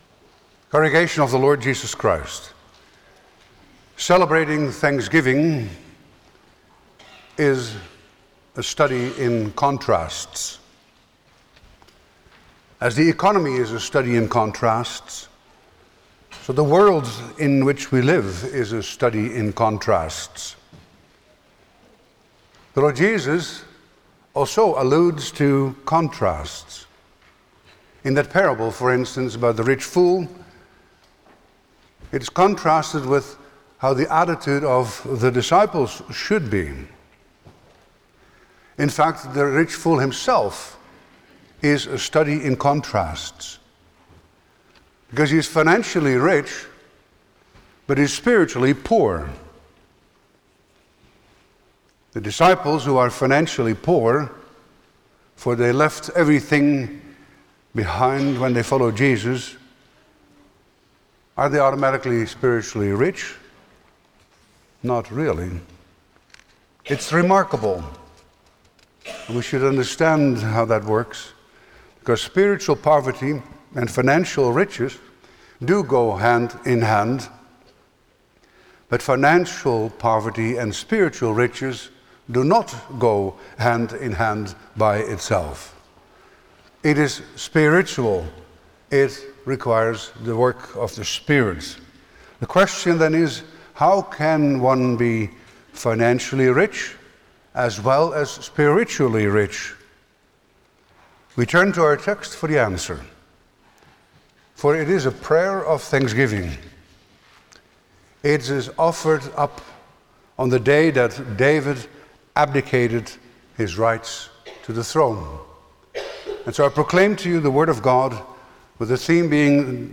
Service Type: Sunday morning
09-Sermon.mp3